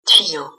According to my French dictionary it’s pronounced /
tɥijo/ – the second symbol represents a voiced labial-palatal approximant, a semi-vowel made with the tongue close to the hard palate and rounded at the lips.
tuyau.mp3